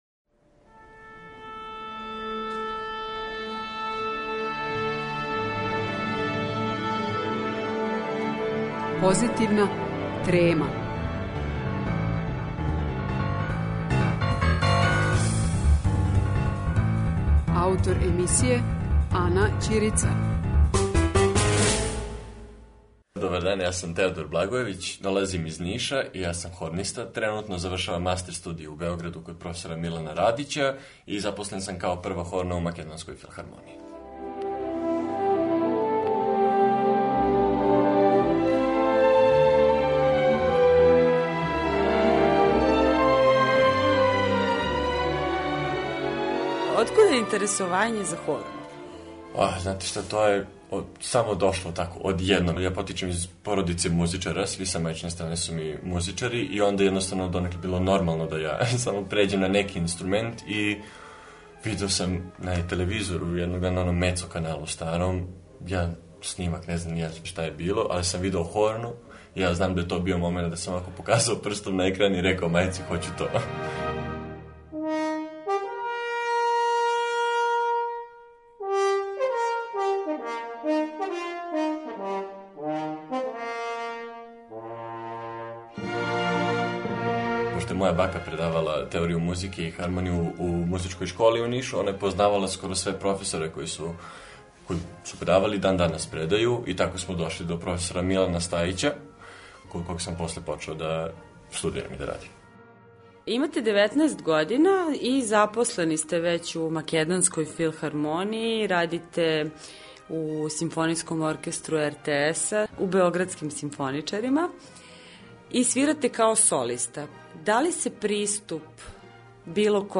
Разговори са музичарима